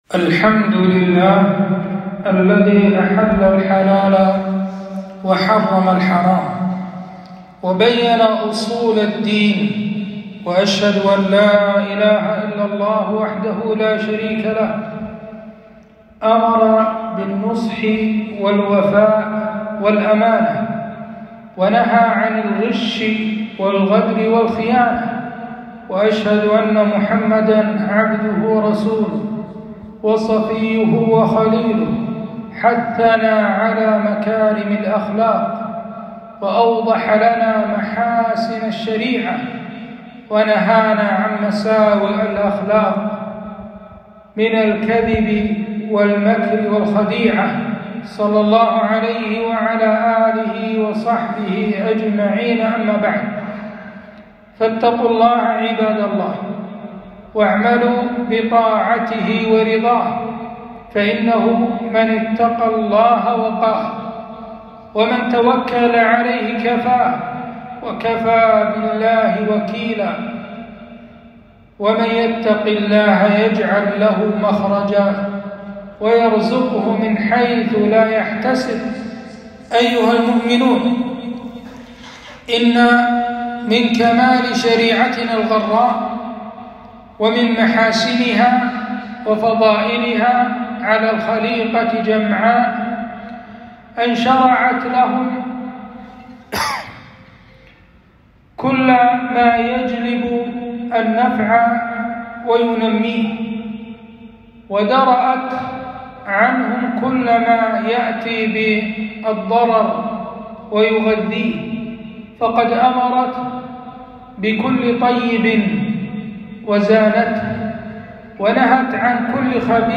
خطبة - حرمة الغش والفساد